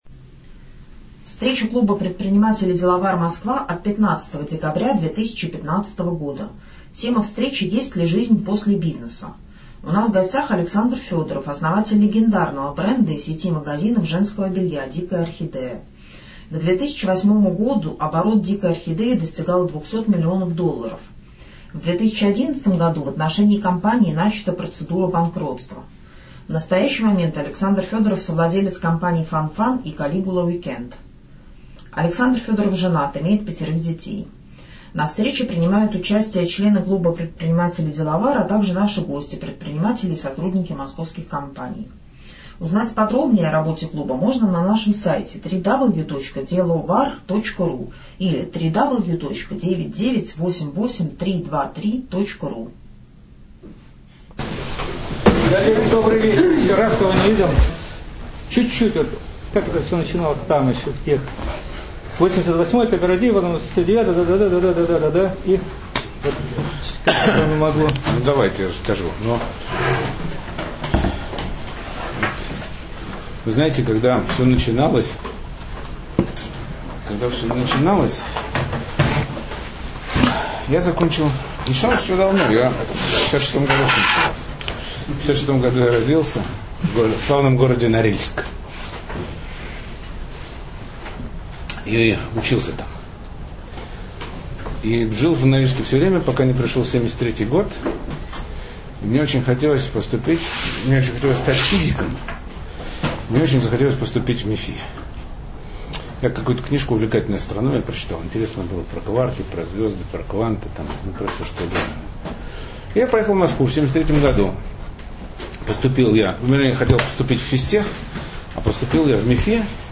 У нас в гостях был Александр Федоров, российский предприниматель, основатель знаменитого бренда женского белья "Дикая Орхидея".